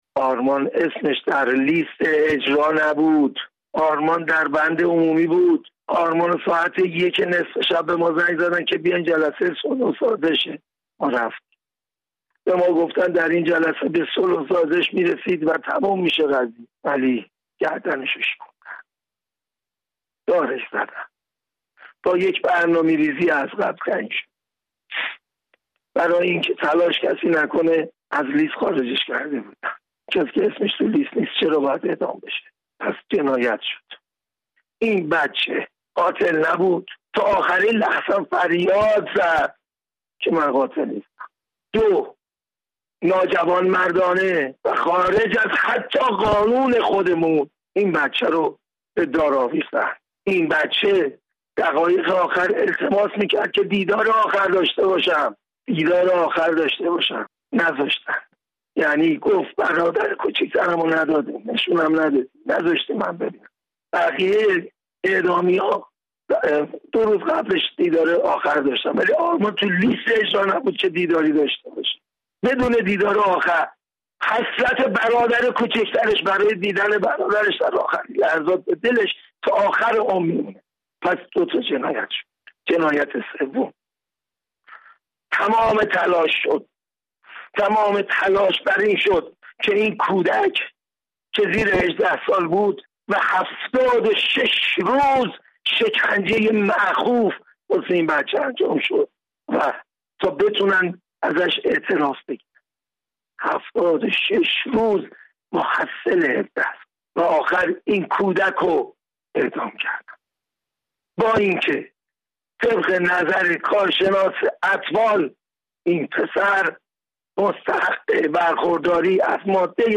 گفت‌وگوی ویژه